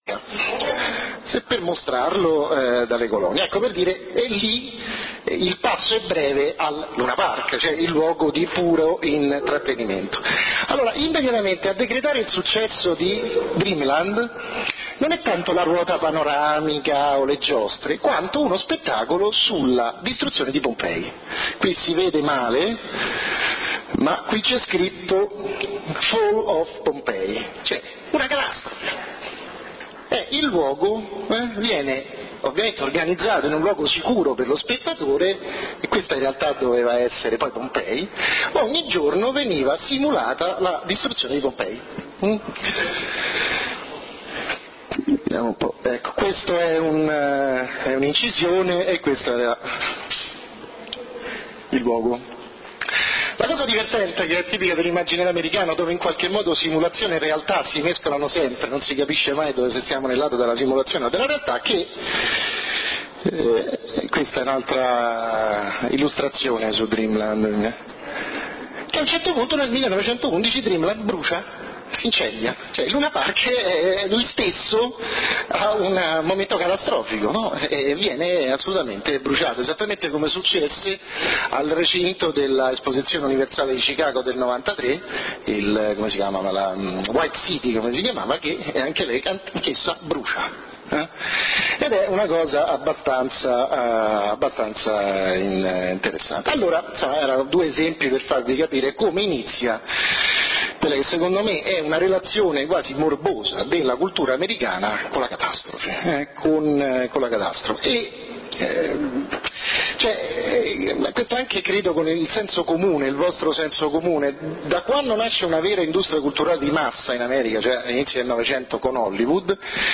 LECTURE / Una modernità senza catastrofe | CCC Strozzina